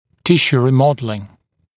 [‘tɪʃuː ˌriː’mɔdəlɪŋ] [-sjuː][‘тишу: ˌри:’модэлин] [-сйу:]ремоделирование тканей